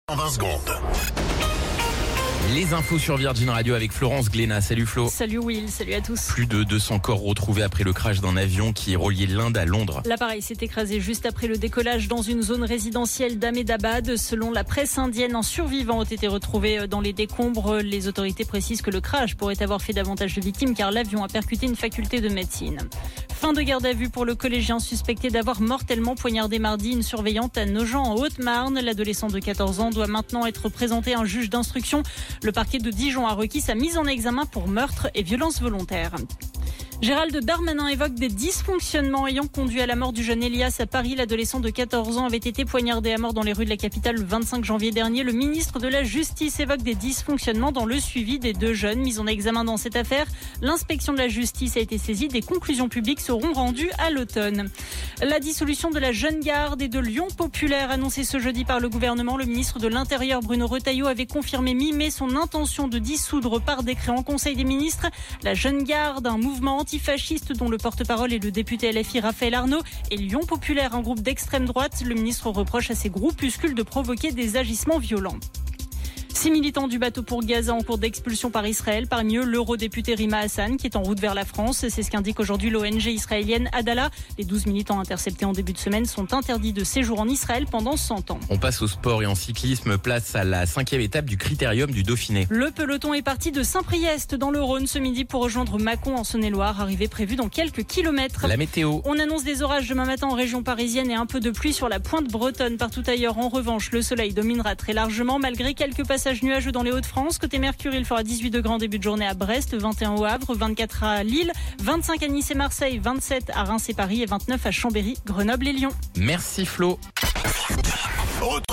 Flash Info National 12 Juin 2025 Du 12/06/2025 à 17h10 .